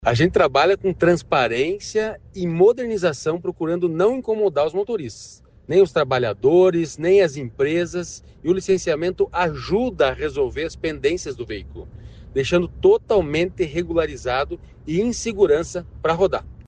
Sonora do diretor-presidente do Detran, Santin Roveda, sobre o calendário de pagamento do licenciamento de veículo